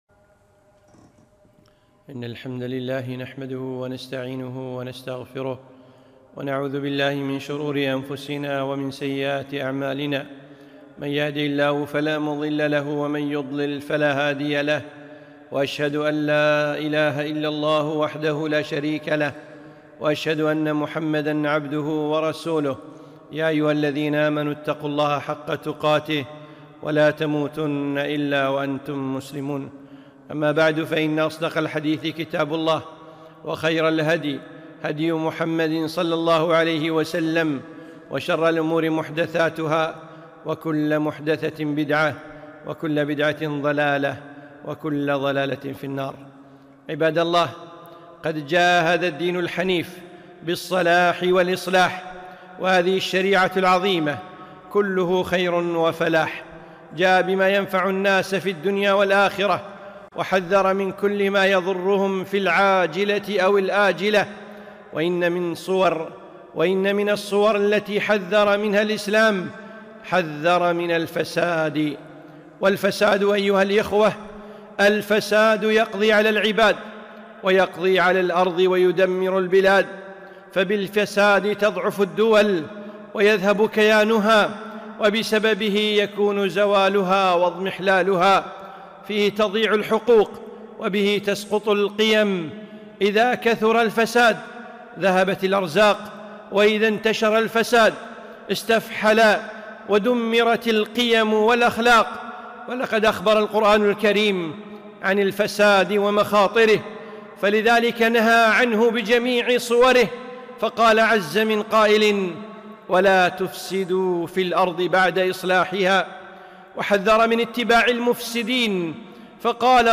خطبة - الحذر من الفساد